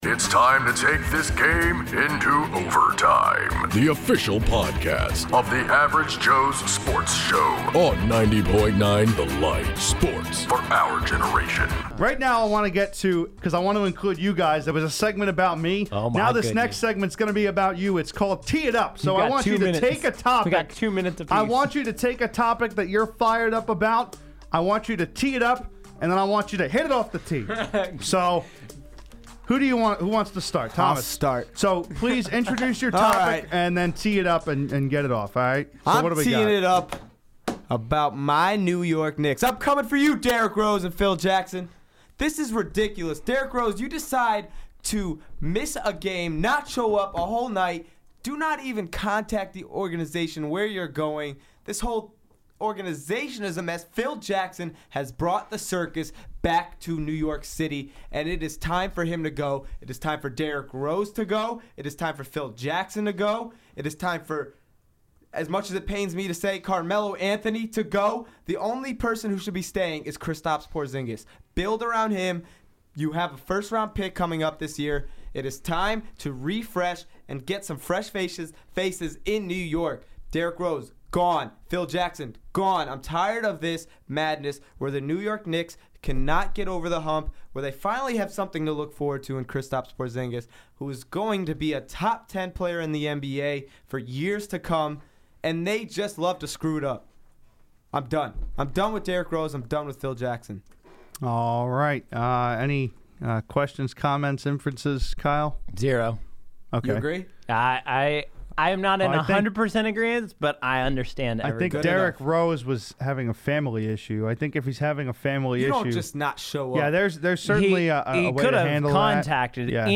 A new segment debuting on the show, analysts